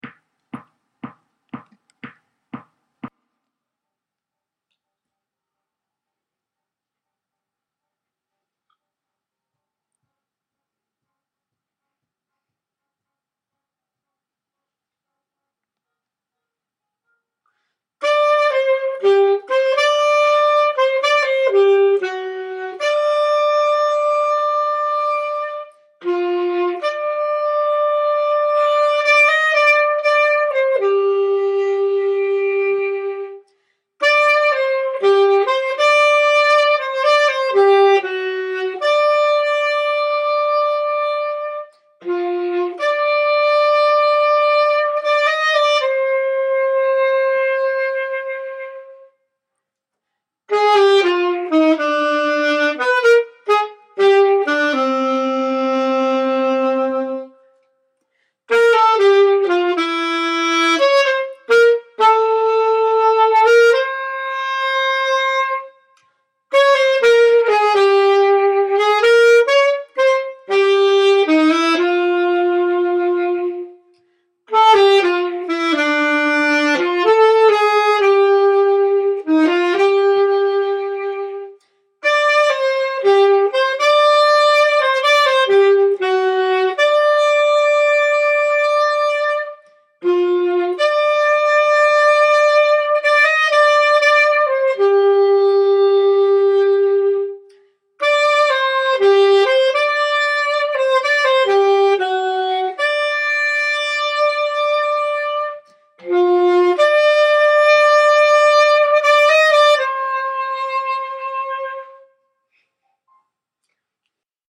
On The Edge Piano Part